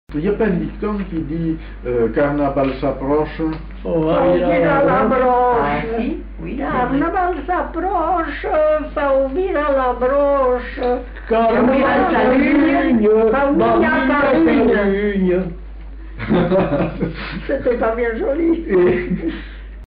Lieu : Captieux
Genre : chant
Type de voix : voix d'homme ; voix de femme
Production du son : chanté
Classification : chanson de carnaval